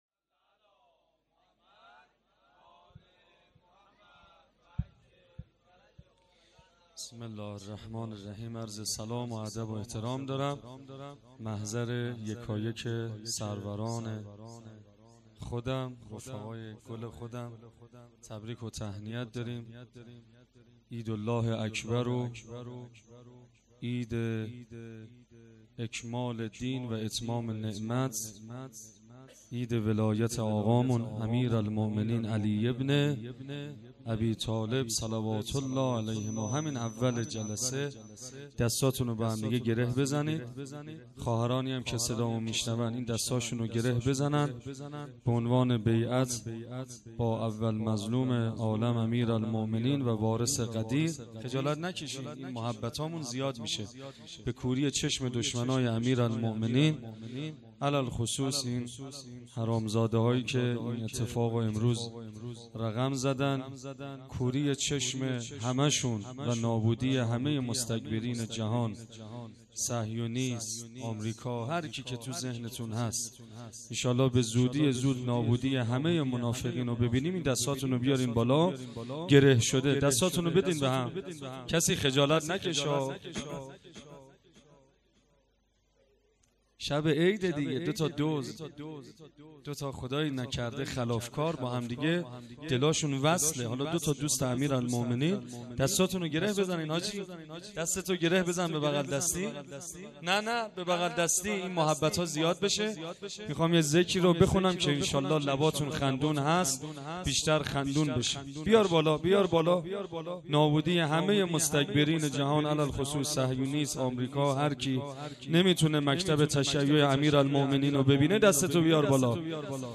مدح
شب دوم جشن عید غدیر ۱۴۰۴